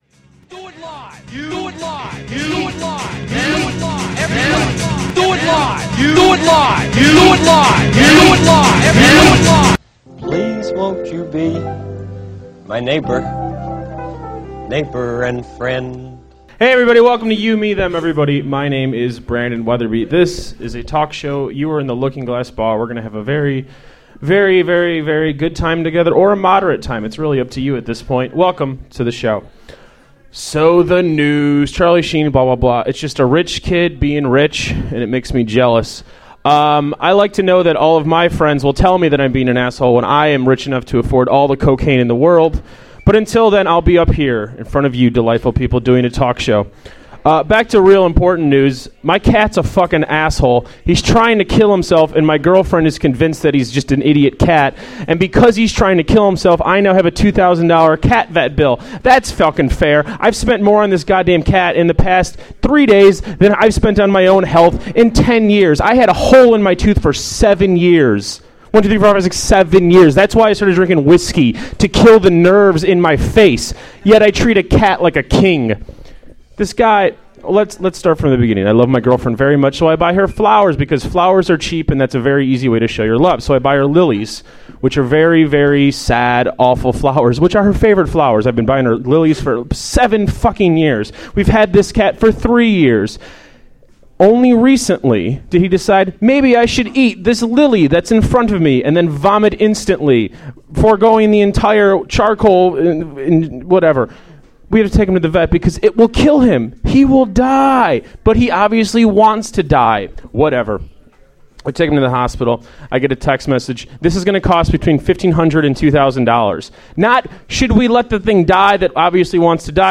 Live at the Looking Glass